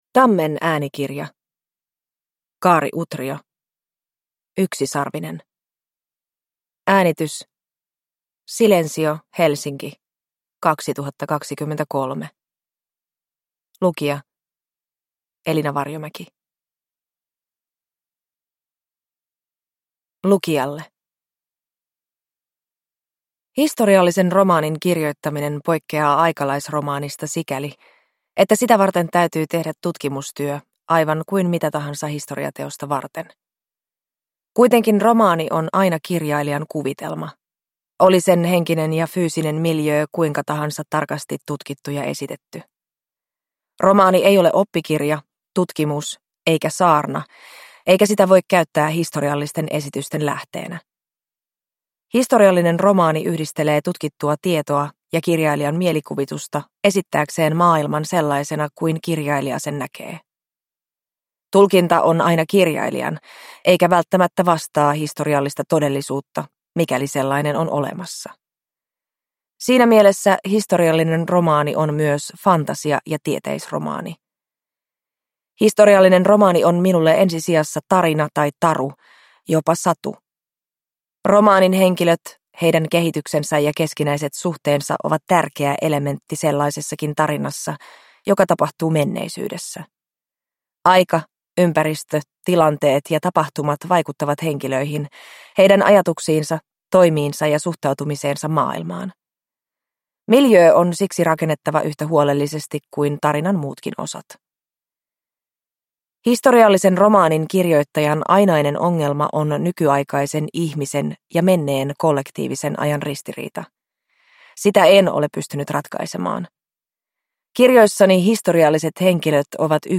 Yksisarvinen – Ljudbok